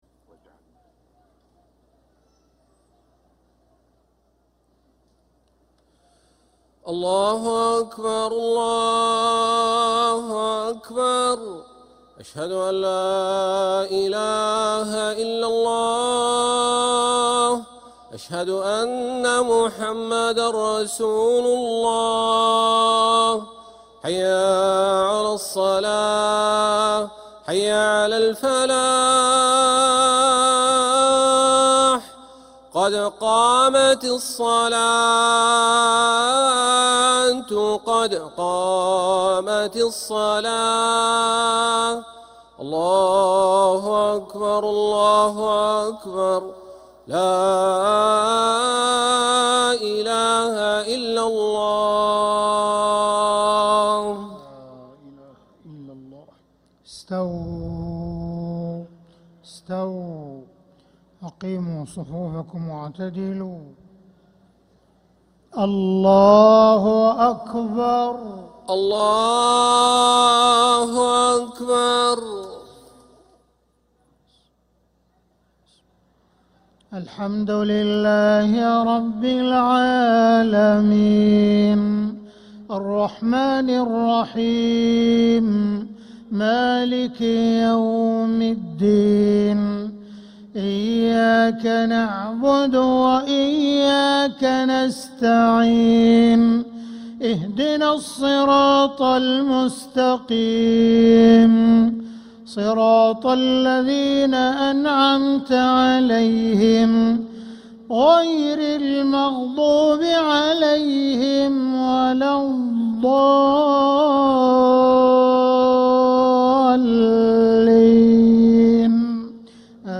Haramain Salaah Recordings: Makkah Isha - 24th April 2026